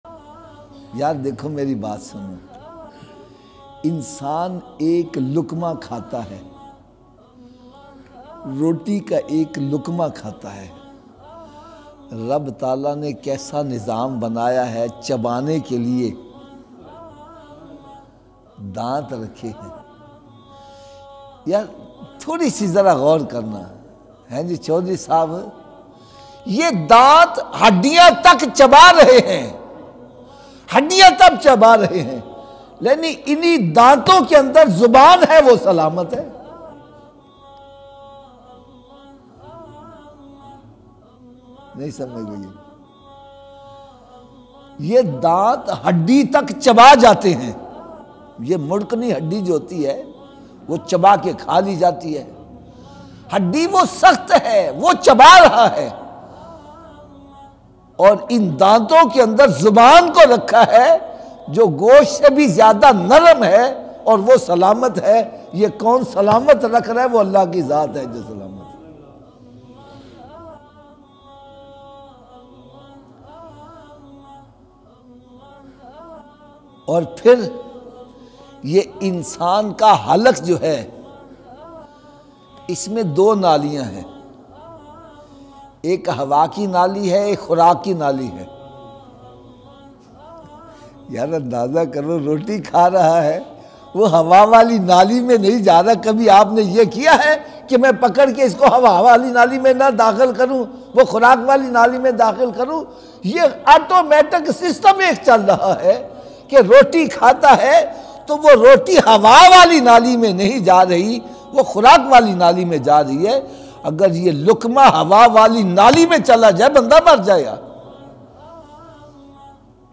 Beautifull Bayan